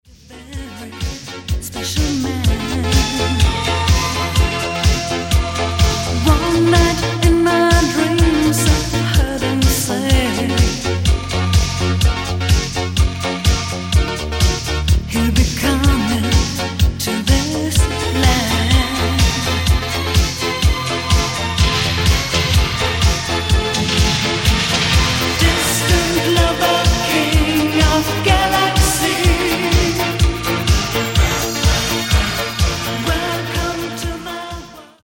Genere:   Disco Elettronica